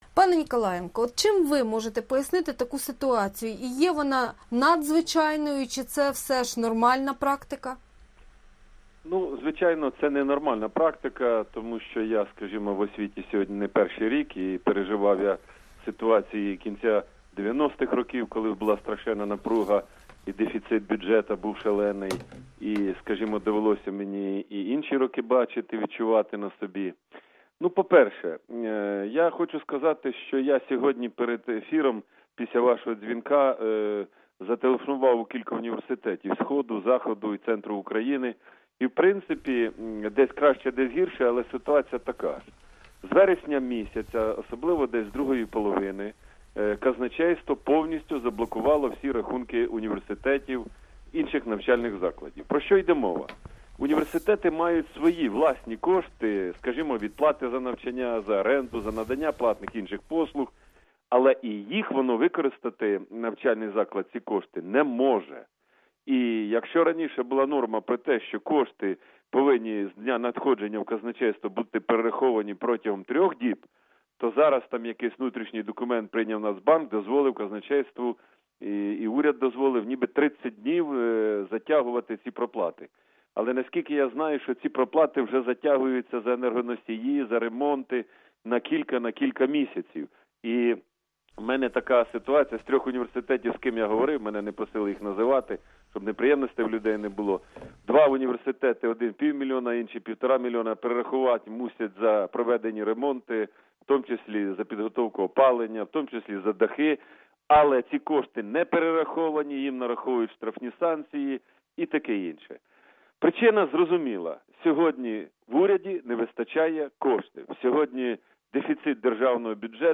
Вищі навчальні заклади України відчувають гострий дефіцит коштів через недофінансування галузі і блокування казначейством їхніх власних рахунків. Про це заявив в ефірі Радіо Свобода голова Громадської Ради освітян і науковців України, колишній міністр освіти Станіслав Ніколаєнко.